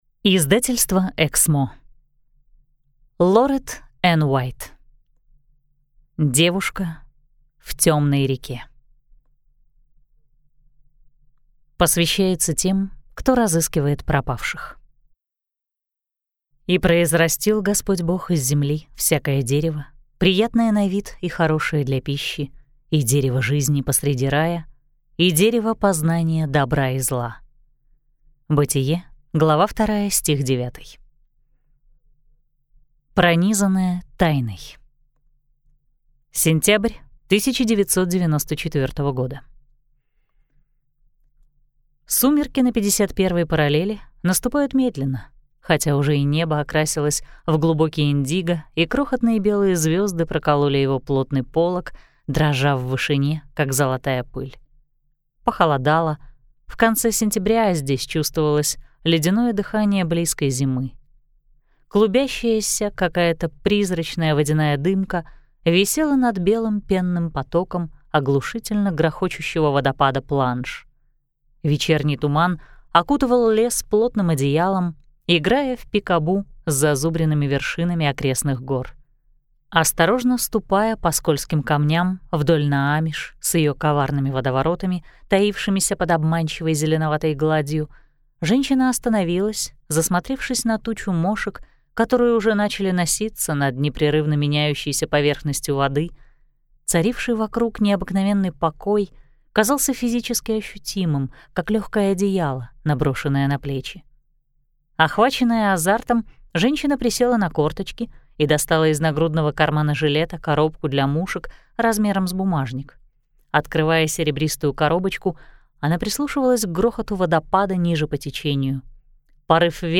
Аудиокнига Девушка в темной реке | Библиотека аудиокниг